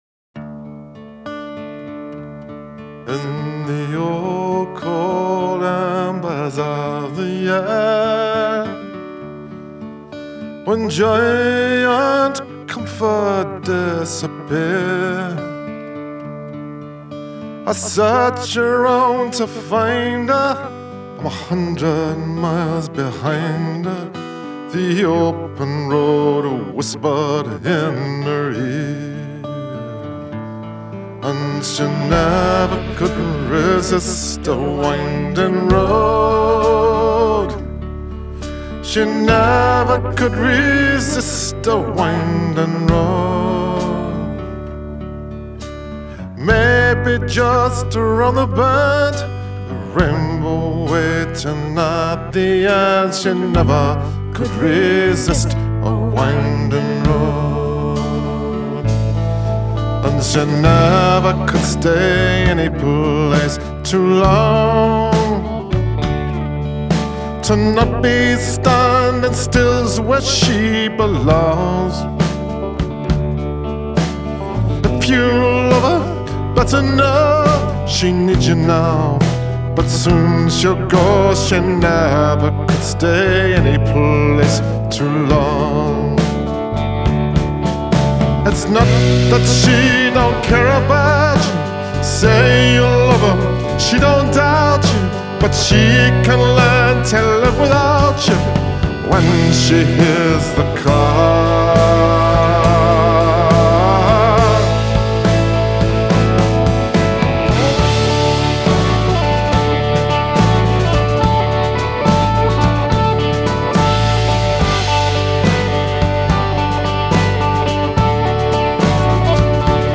the playing is solid and unadorned.